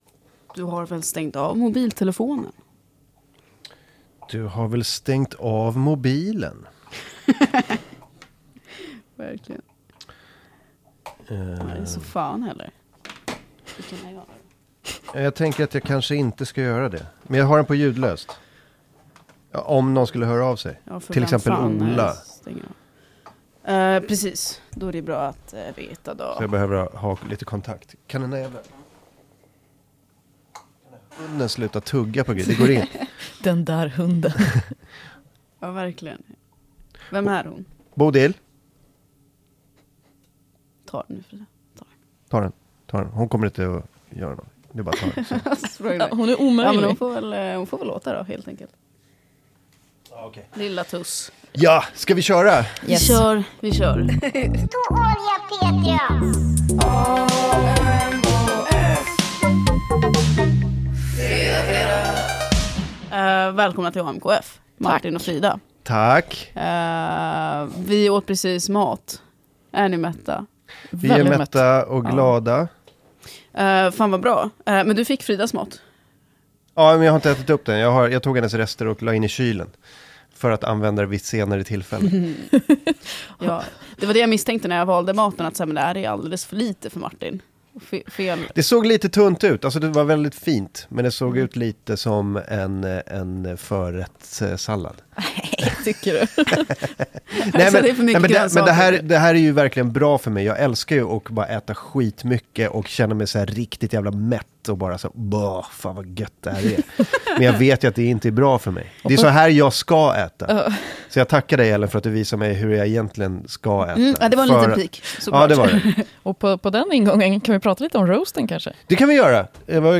Upplevde dock lite sprak i bakgrundsljudet när jag lyssnade med hörlurar, hoppas det är går bort med finliret.